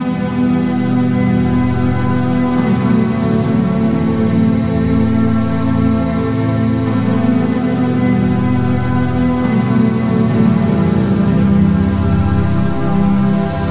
描述：垫环
Tag: 90 bpm Hip Hop Loops Pad Loops 1.79 MB wav Key : Unknown